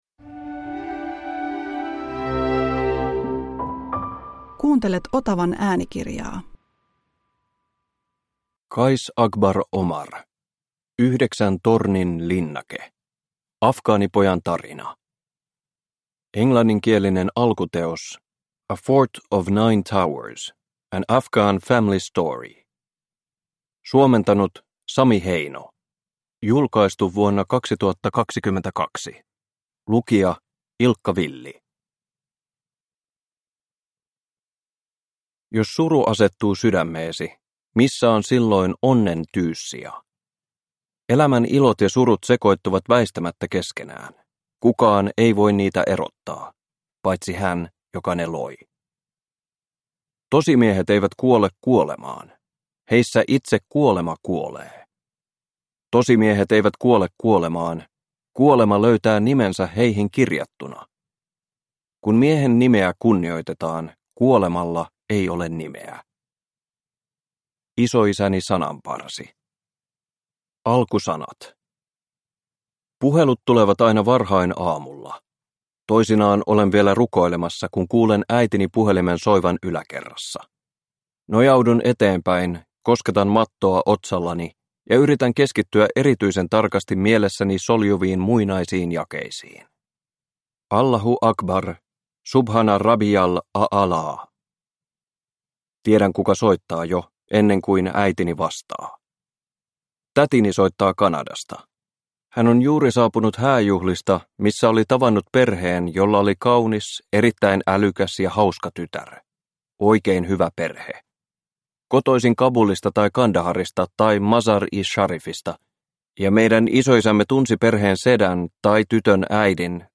Yhdeksän tornin linnake – Ljudbok
Uppläsare: Ilkka Villi